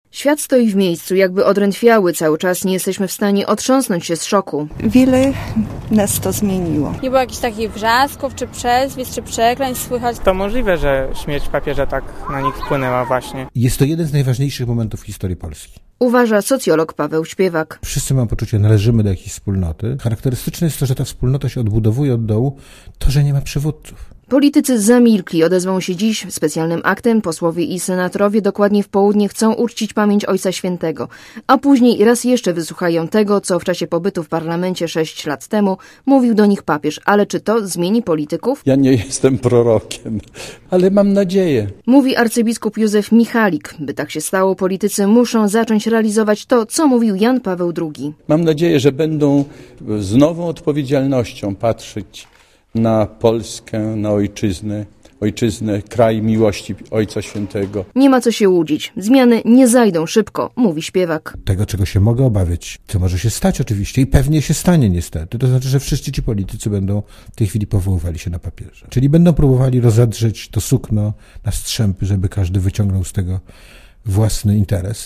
(PAP) Tak mówią słuchacze Radia ZET, tak też mówią przypadkowo spotykani ludzie na ulicach polskich miast.
Relacja